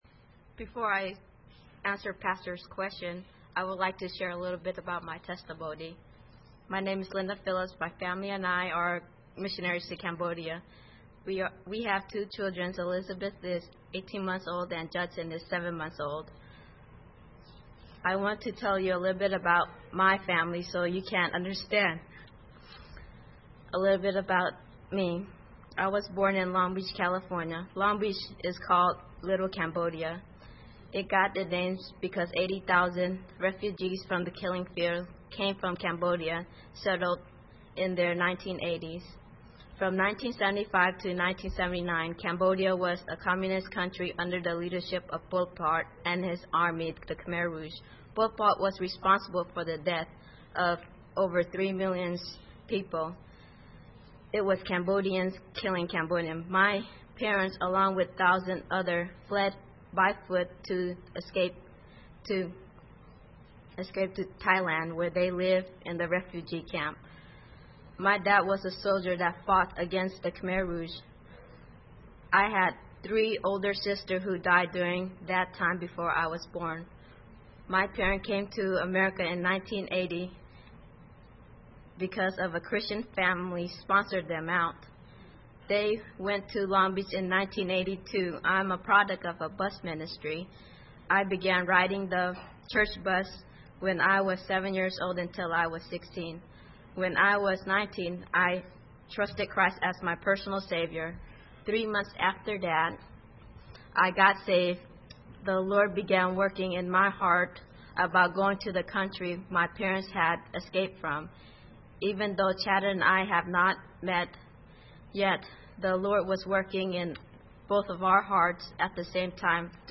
Series: 2009 Missions Conference Service Type: Special Service